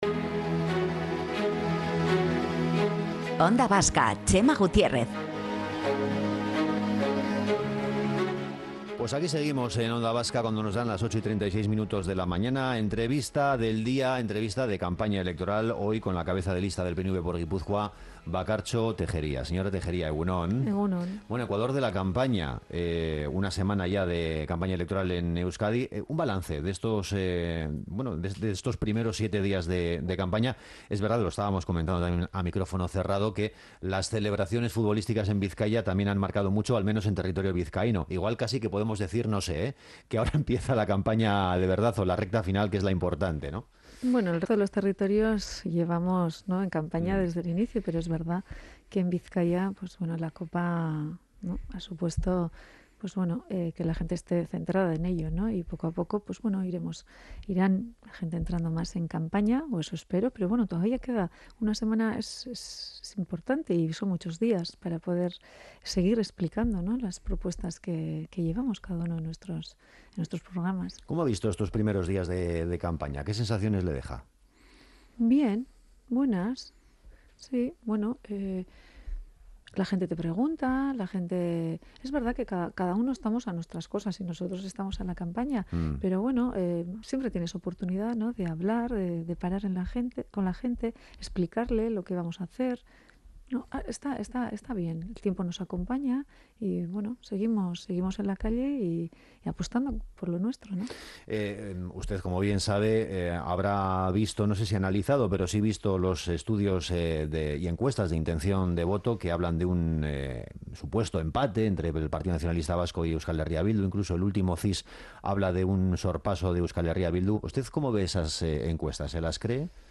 Entrevistas de campaña: Bakartxo Tejería, cabeza de lista del PNV por Gipuzkoa - Onda Vasca